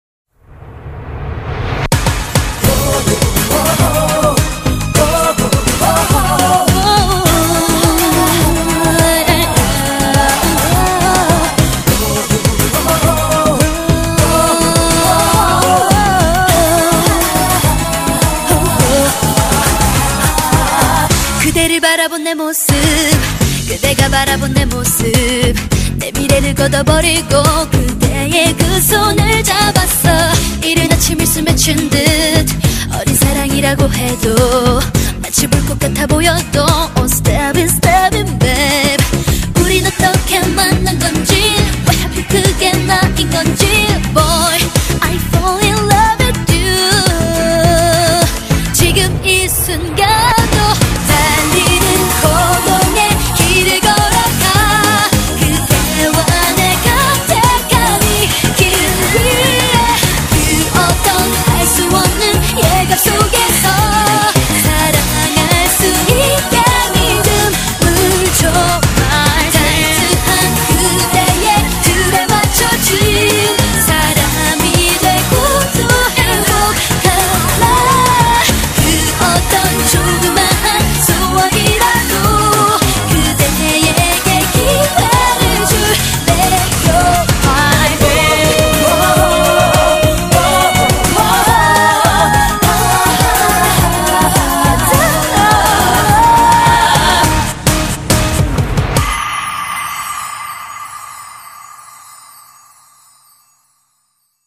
BPM104--1
Audio QualityPerfect (High Quality)